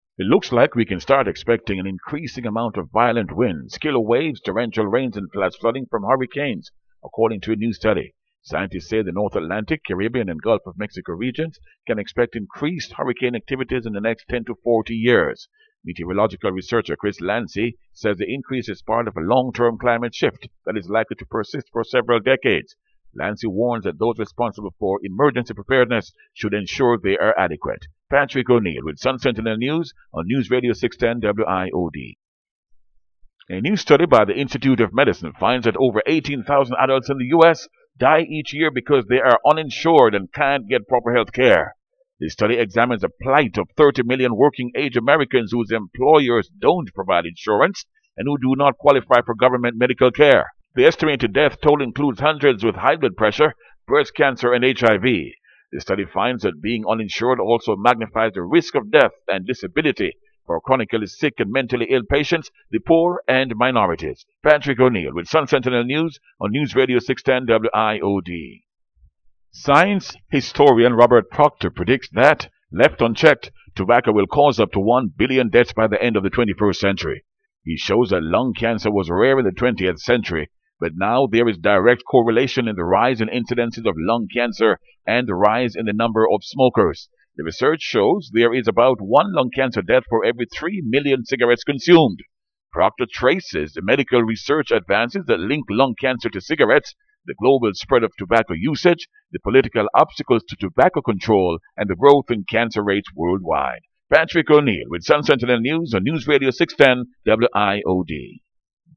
They are excerpts of his news reading, formerly aired during weekdays on WIOD 610 AM; located in Miramar, Florida.